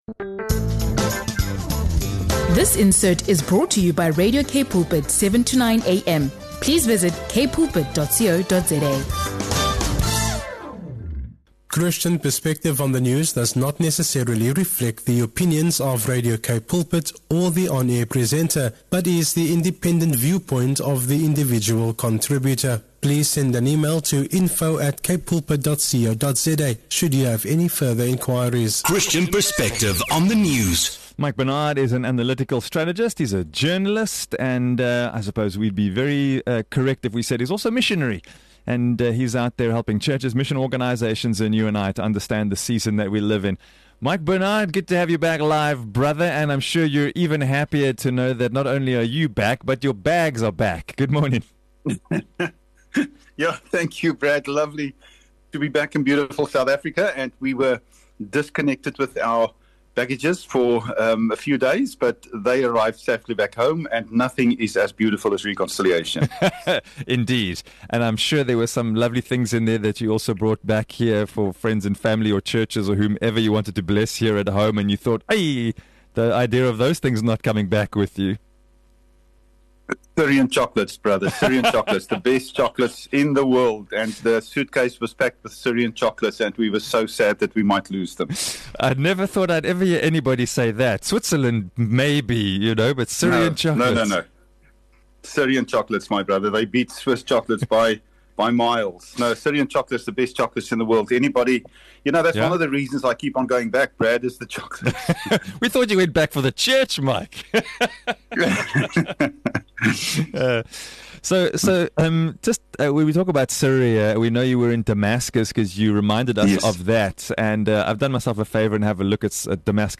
In this insightful interview